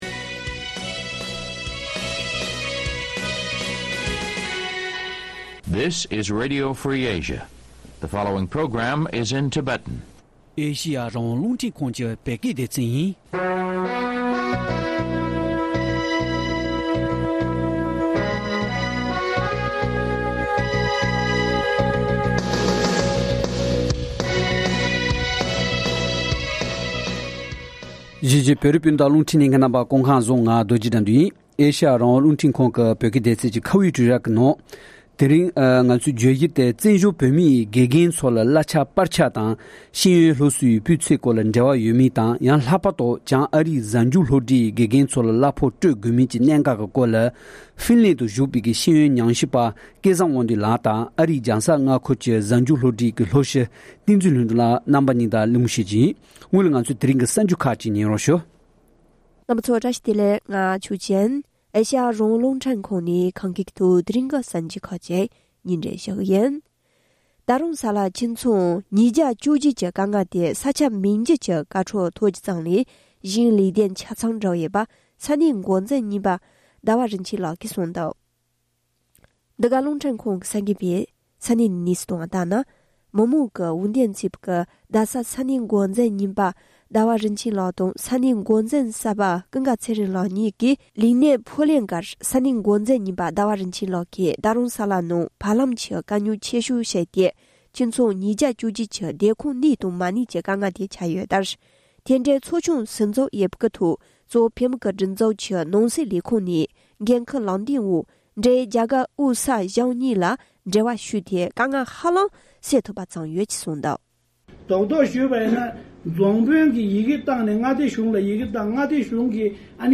བཙན་འབྱོལ་བོད་མིའི་དགེ་རྒན་ཚོར་གླ་ཆ་སྤར་ཆ་དང་ཤེས་ཡོན་སློབ་གསོའི་སྤུས་ཚད་སྐོར་འབྲེལ་བ་ཡོད་མིན་སྐོར་གླེང་བ།